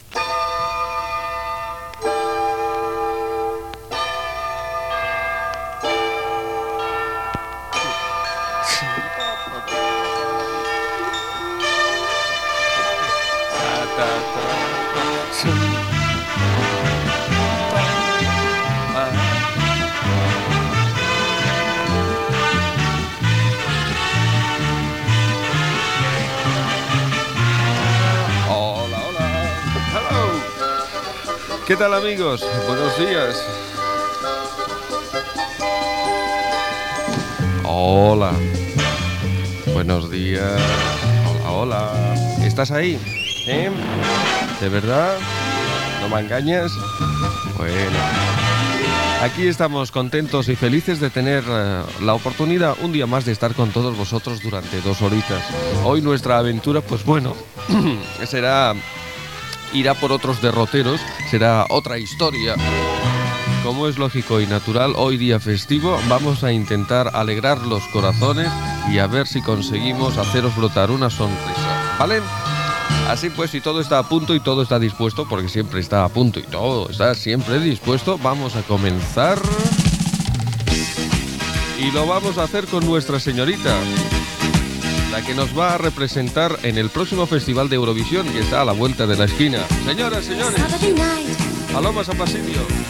Inici del programa, desig de bon dia, tema musical
Entreteniment